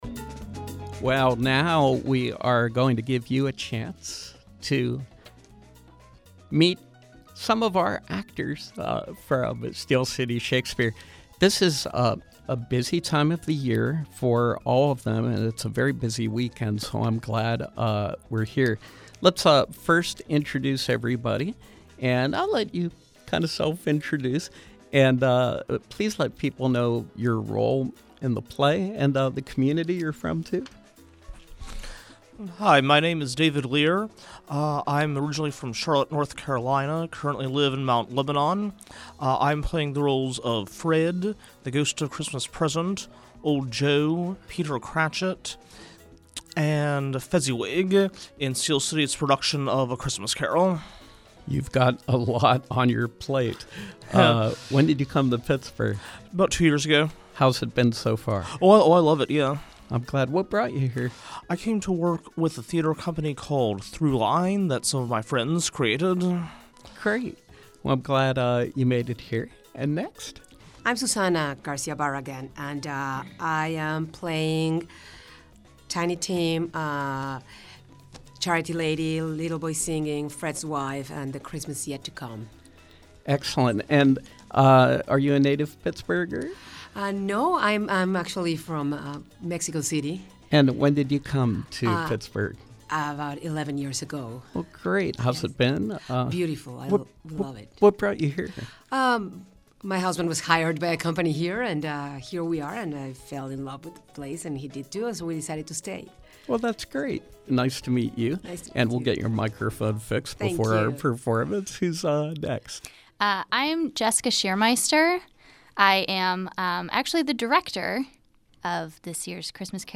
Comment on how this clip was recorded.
In Studio Pop-up: Steel City Shakespeare, A Christmas Carol